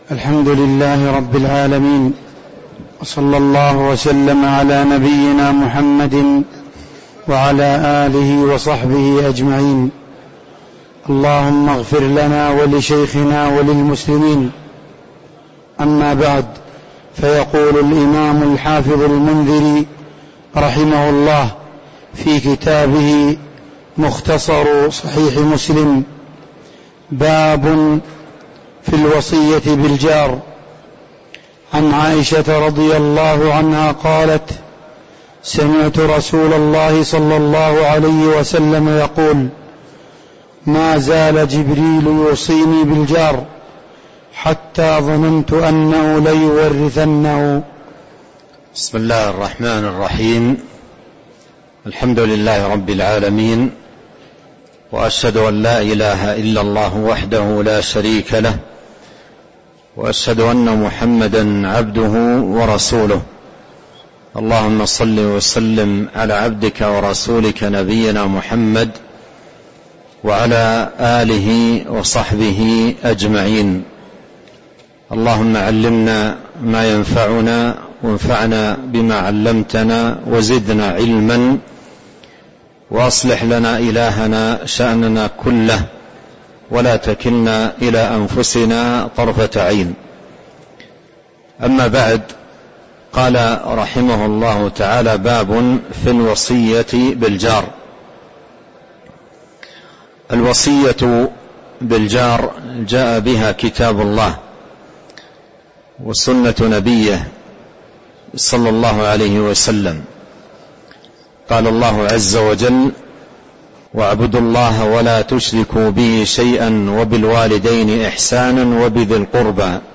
تاريخ النشر ٢٩ رمضان ١٤٤٣ هـ المكان: المسجد النبوي الشيخ: فضيلة الشيخ عبد الرزاق بن عبد المحسن البدر فضيلة الشيخ عبد الرزاق بن عبد المحسن البدر باب في الوصية بالجار (08)